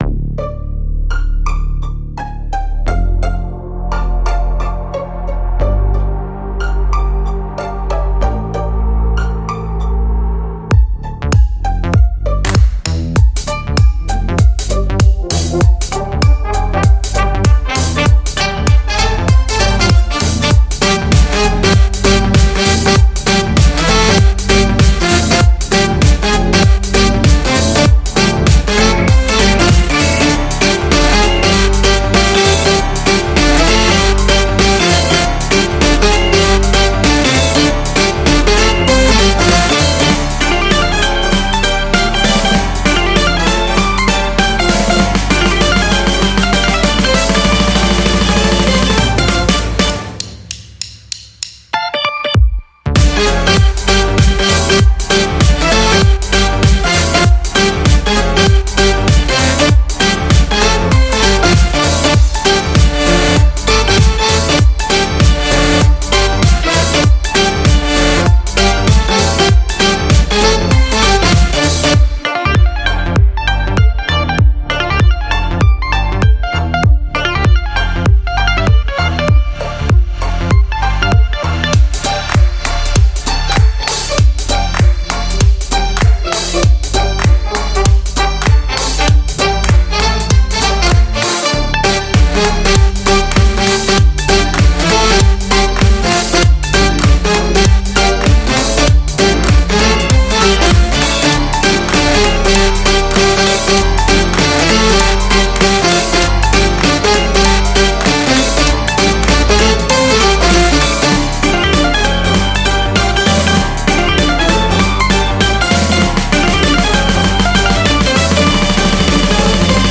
música energética, música alegre